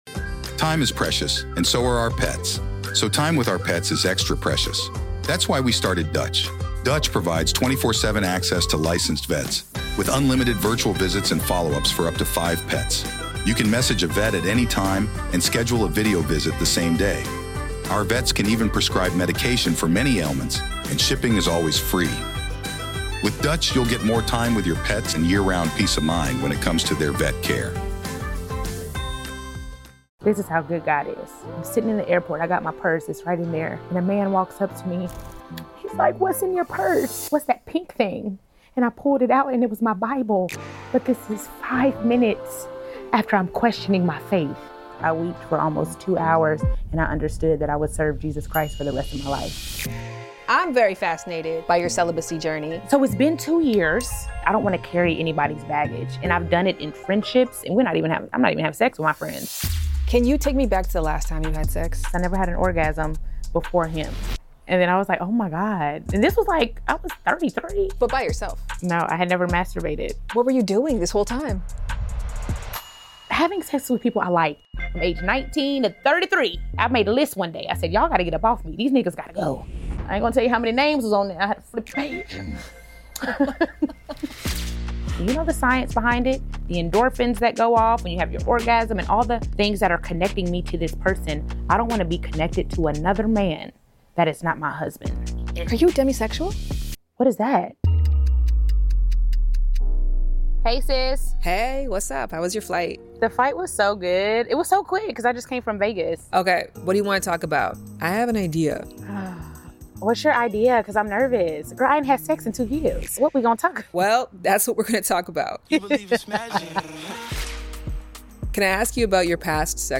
In this thought-provoking episode, Shan Boodram sits down with B. Simone to discuss the transformative journey of embracing chastity, understanding soul ties, and exploring the nuances of identity a...
While B. Simone does not identify as demisexual, Shan suggests the possibility during their candid conversation, sparking an insightful dialogue about emotional intimacy and sexual attraction.